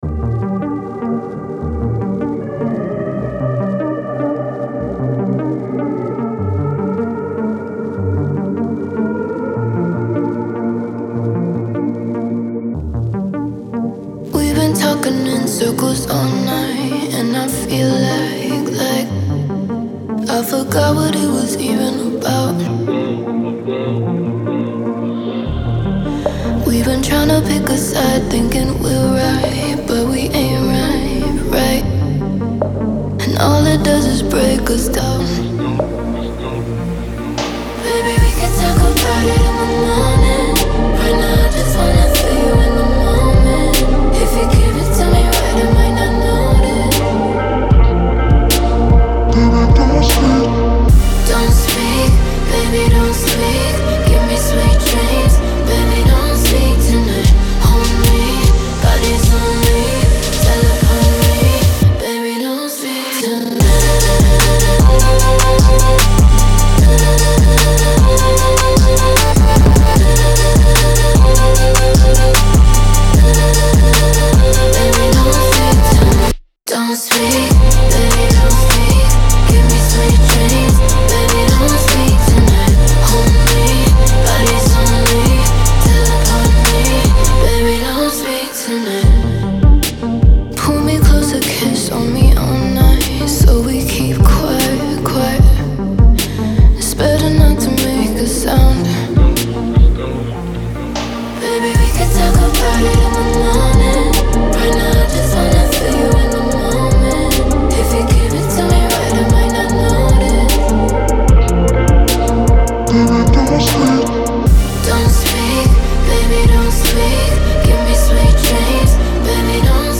• Жанр: Trap